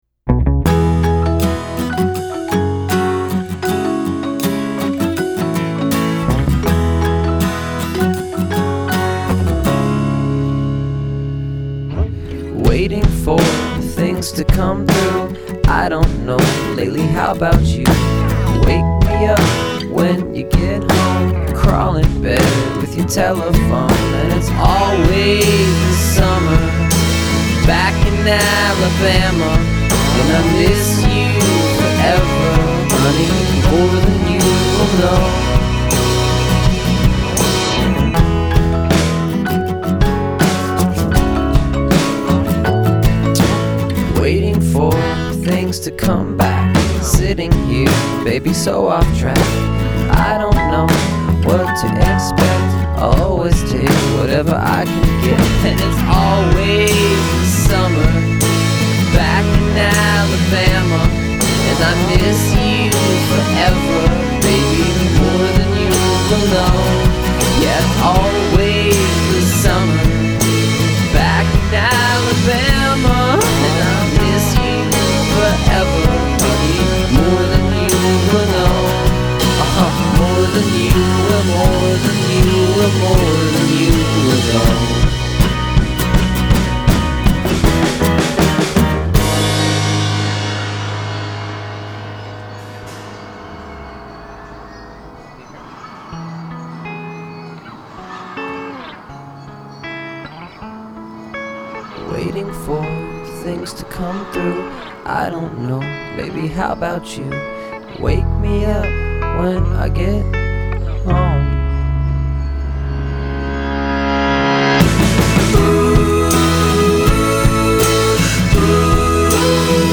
one man band